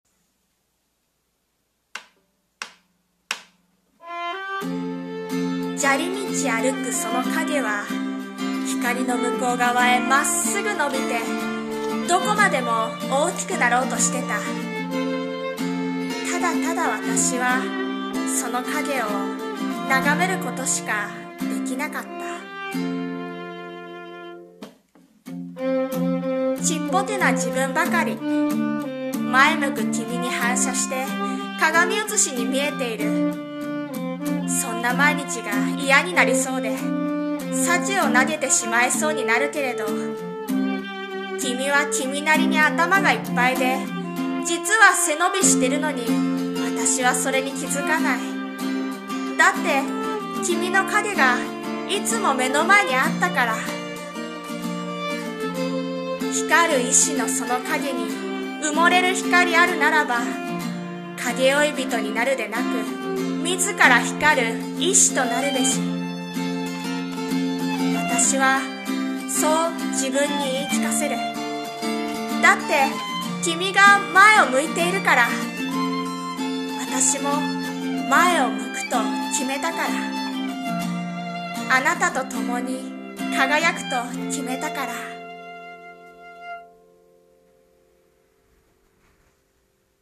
さんの投稿した曲一覧 を表示 朗読台本「光る原石〜ヒカルイシ〜」